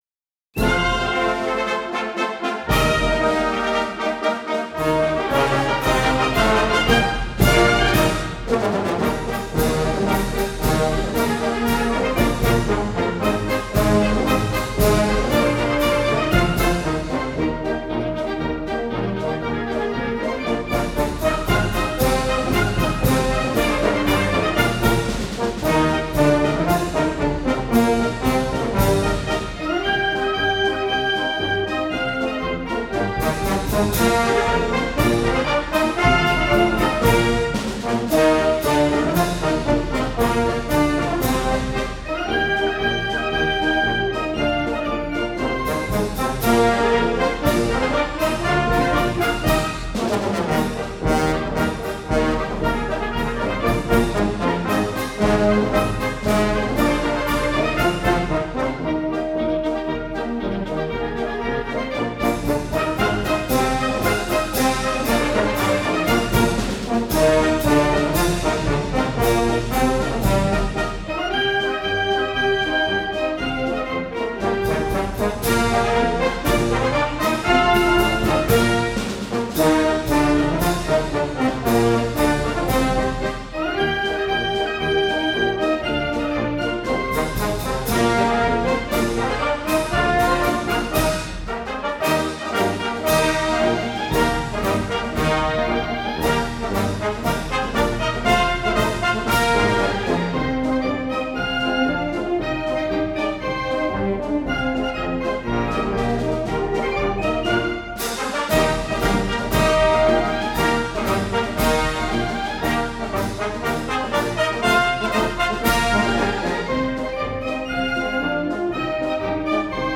军乐